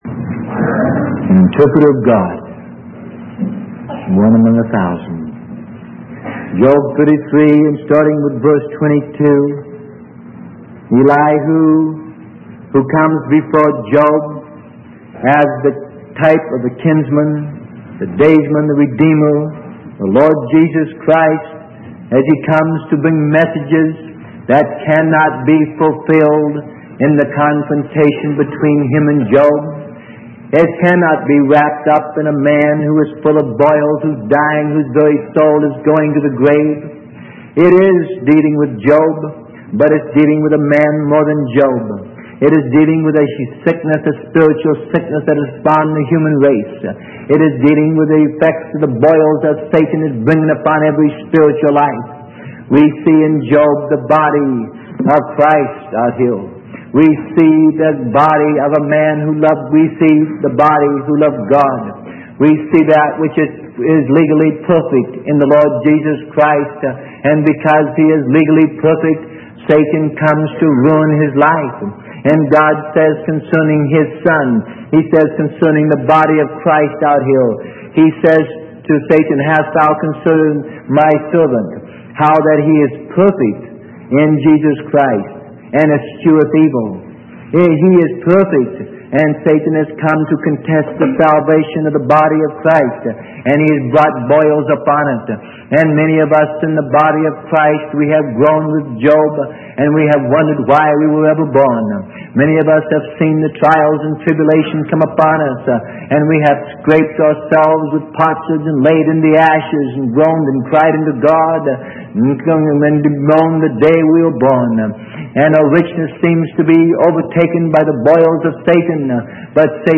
Sermon: Interpreters of God - Part 1 - Job 33:22-24 - Freely Given Online Library